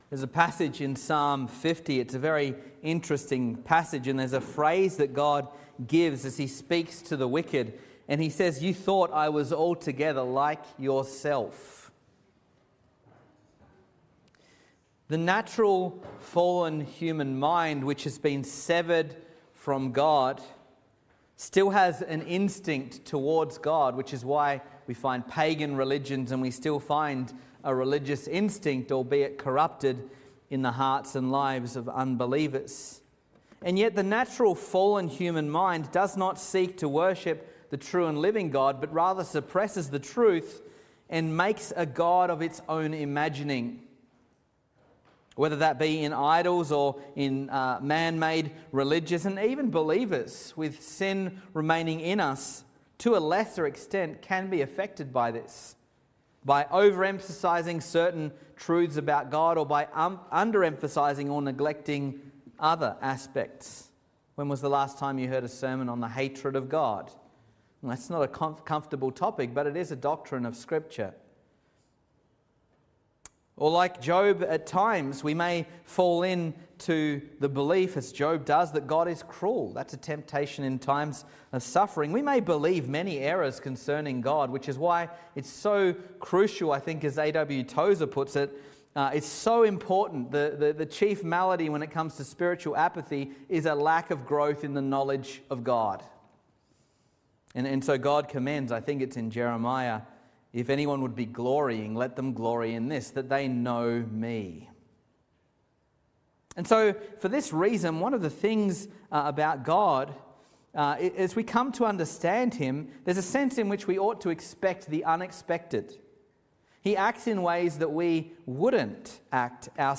Sermons | Reformed Church Of Box Hill